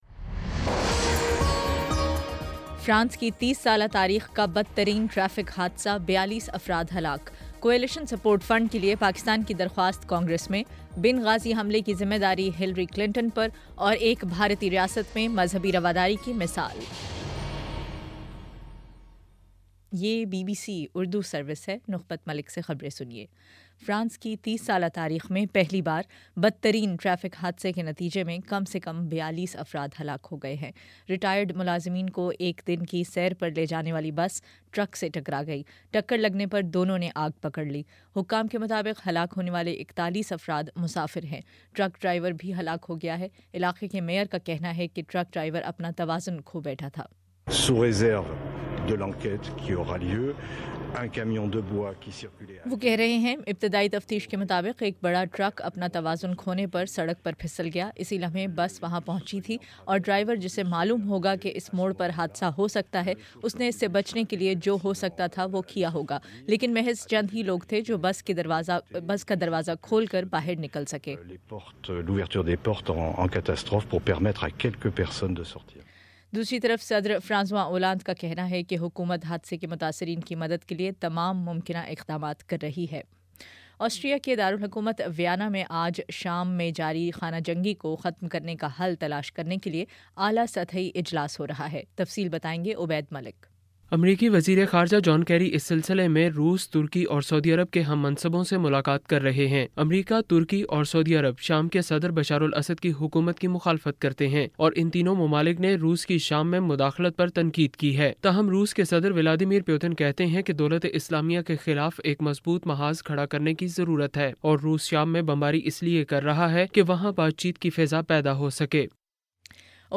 اکتوبر 23 : شام پانچ بجے کا نیوز بُلیٹن